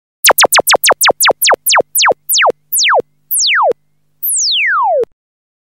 Keterangan: Nada Dering Tembak Laser, Nada Dering Grab Gojek Ojol Notification Tembak Laser Biasa dipakai Ojek Online...
nada-dering-laser-ojol-id-www_tiengdong_com.mp3